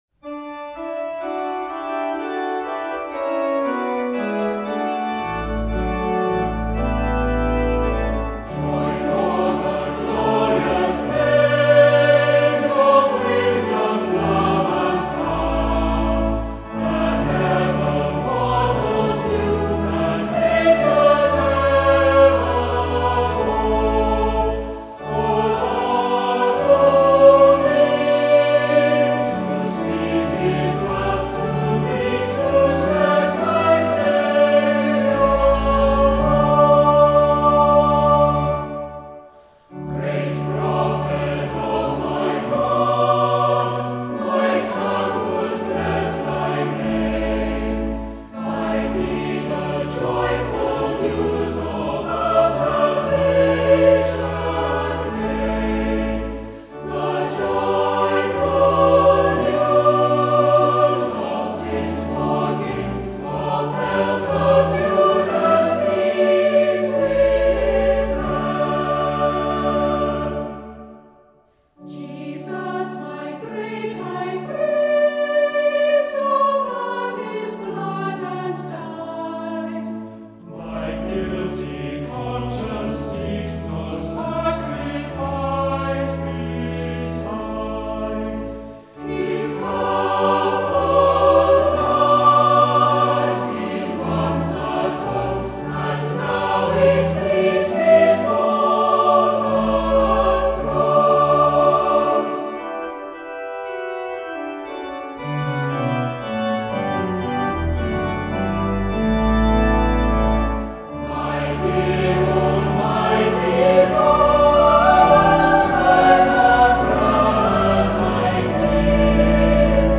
The words to this hymn by Isaac Watts are so glorious …